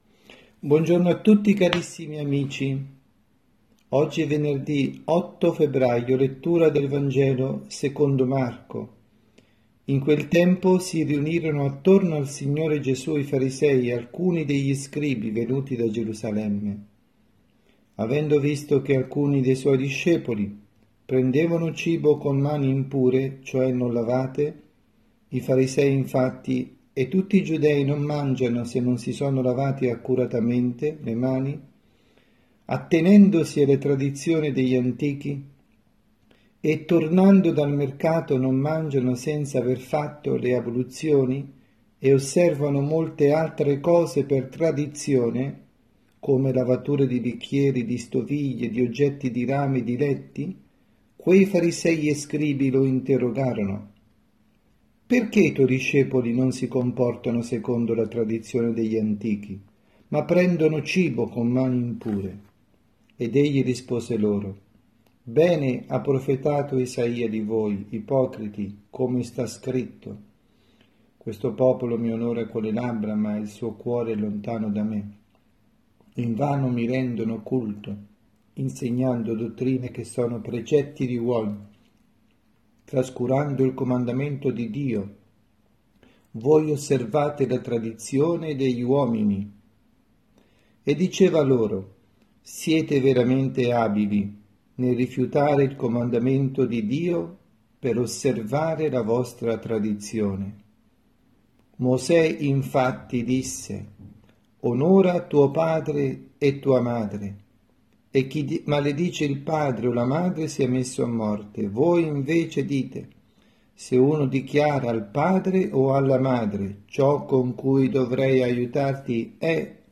Omelia
dalla Casa di Riposo Santa Marta  – Milano